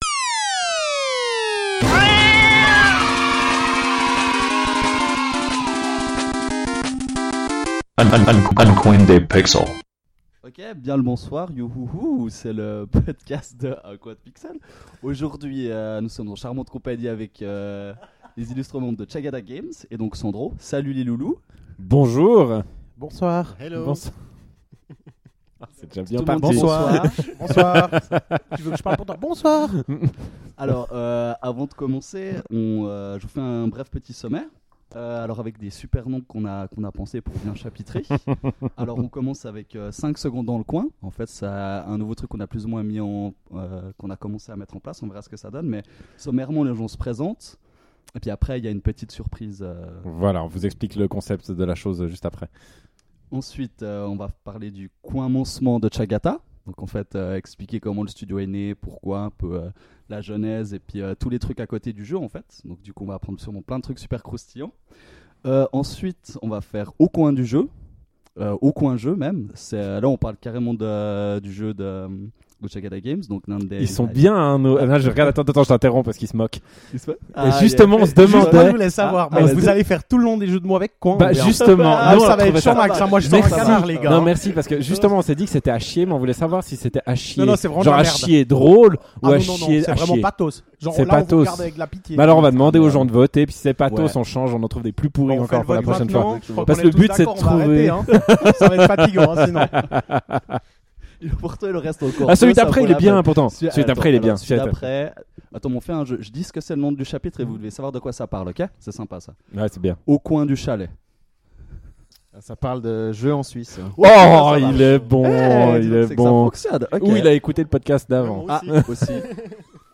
Interview Tchagata Games